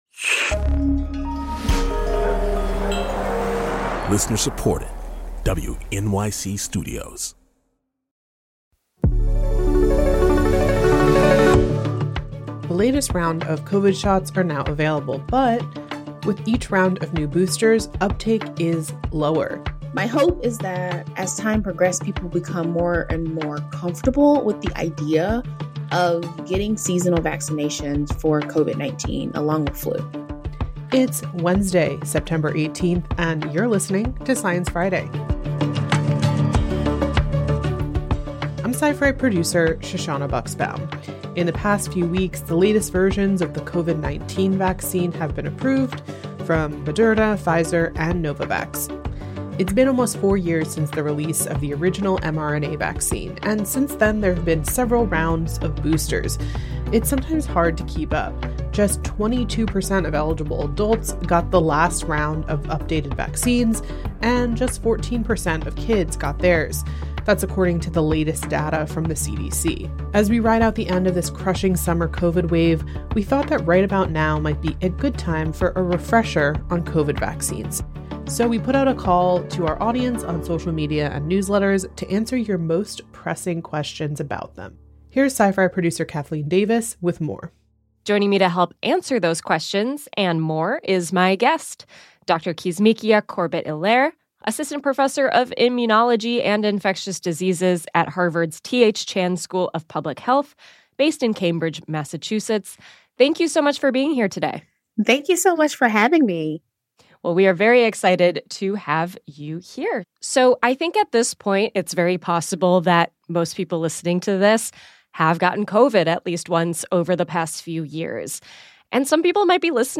Immunologist Dr. Kizzmekia Corbett-Helaire answers audience questions about when to get the latest COVID shot, rapid test efficacy, and more.